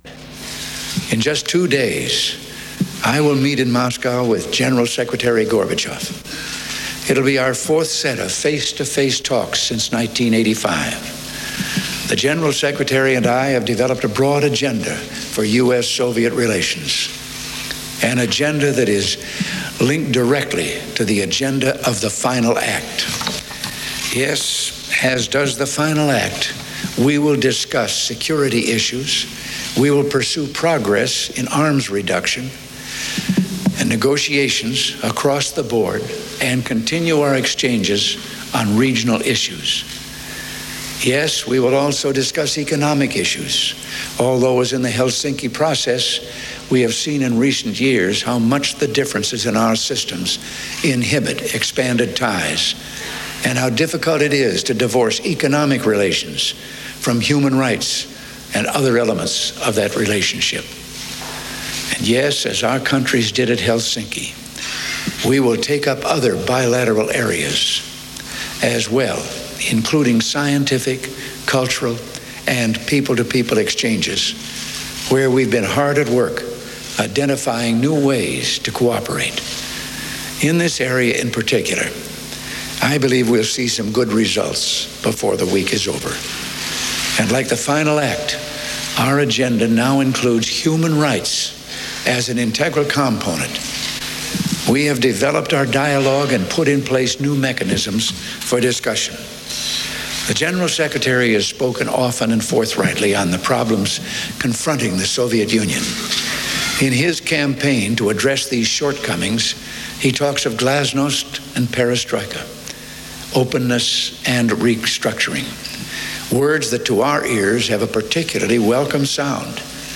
Ronald Reagan speaks on the eve of his summit meeting with Mikhail Gorbachev in Helsinki, Finland